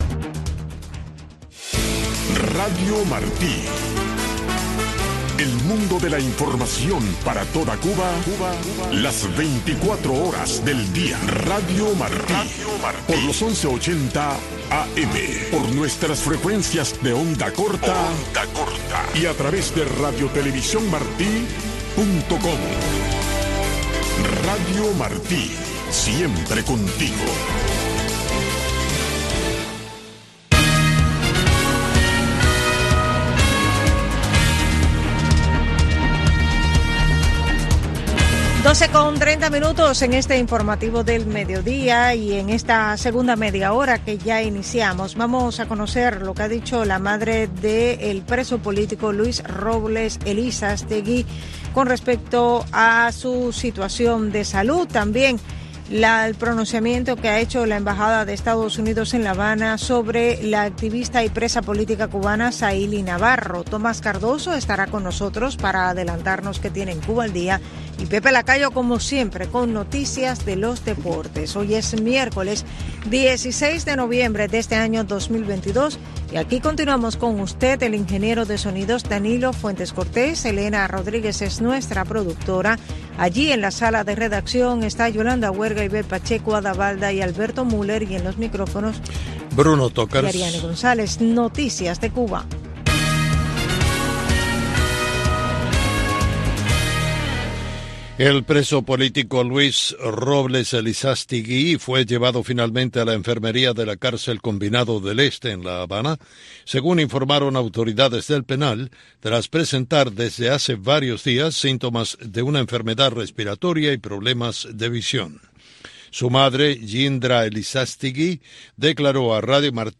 Noticiero de Radio Martí 12:00 PM | Segunda media hora